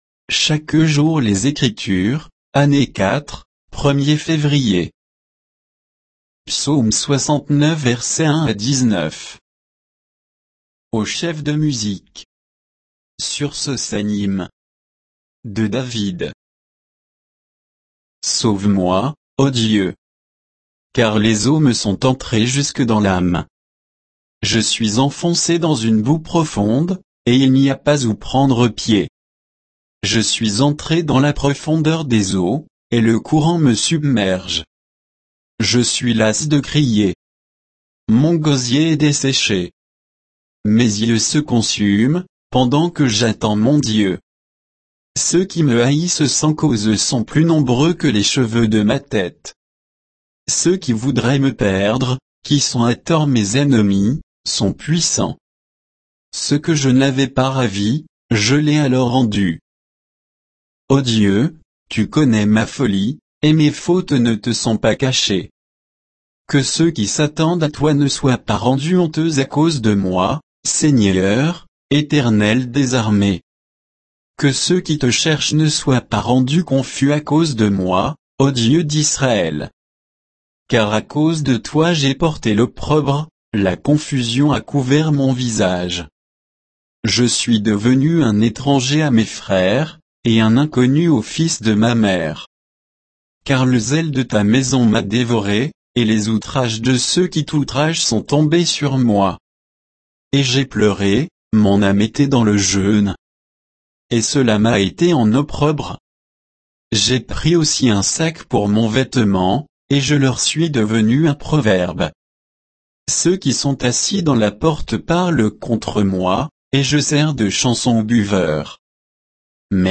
Méditation quoditienne de Chaque jour les Écritures sur Psaume 69